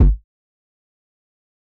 NIX - Atlanta (Kick).wav